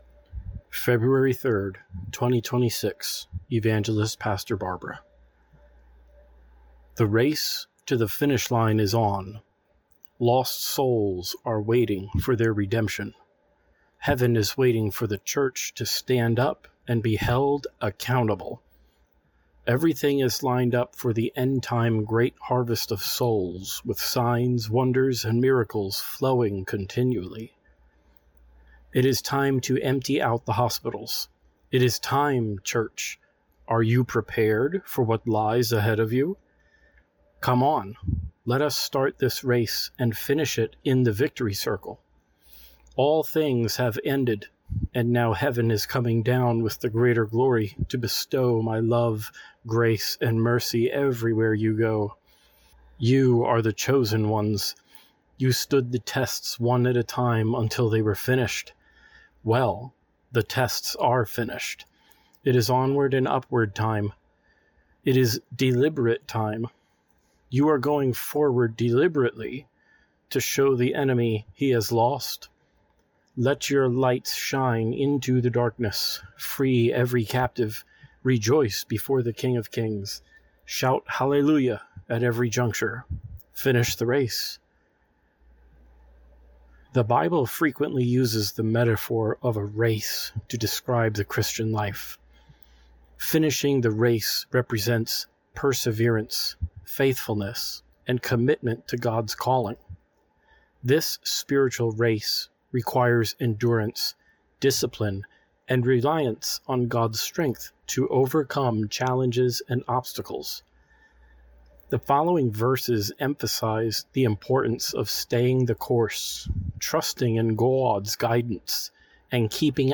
Click The Play button to have the teaching read to you. Attached and below are the notes for you to be able to follow along.